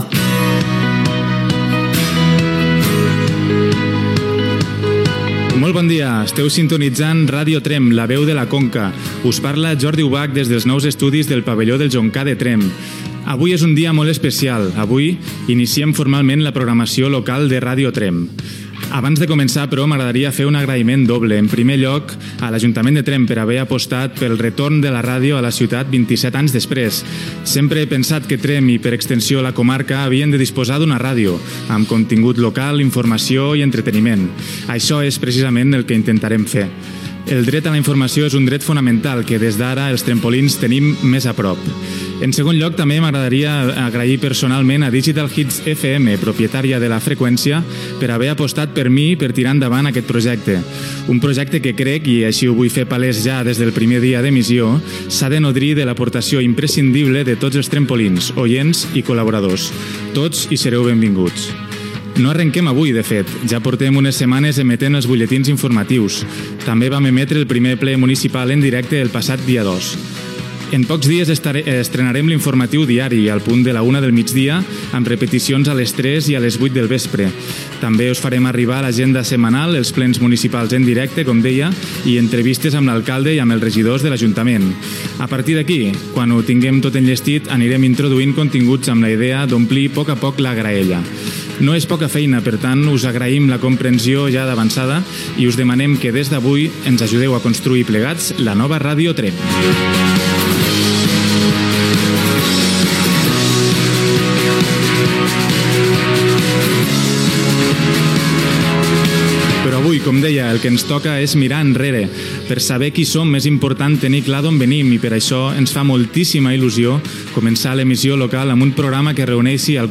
a6ce5b741fd4cde254ed028ac677bd5a45aeb699.mp3 Títol Ràdio Tremp Emissora Ràdio Tremp Cadena Digital Hits FM Titularitat Privada local Nom programa Ràdio Tremp 27 anys després Descripció Programa especial el dia de l'inici de la programació de la tercera etapa de Ràdio Tremp. Es recorda l'emissora que va funcionar de 1957 a 1965 i l'inici de l'emissora municipal, creada l'any 1980.